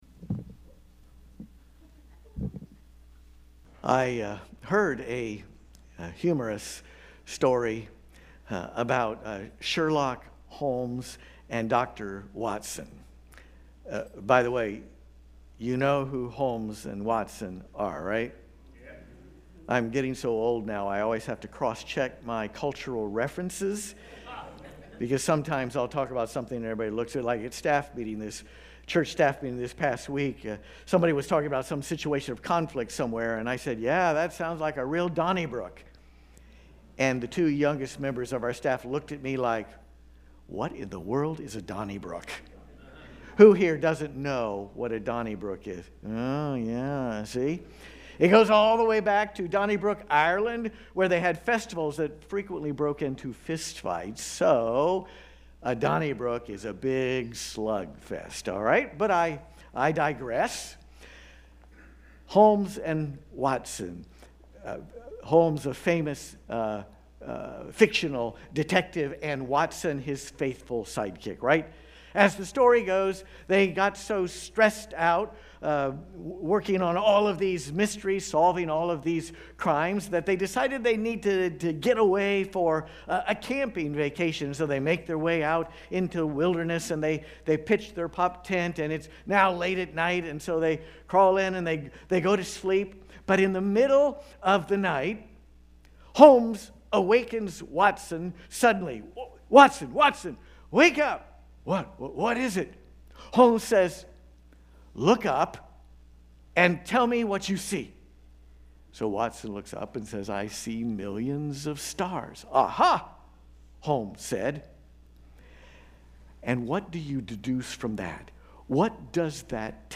Watch the entire Worship Service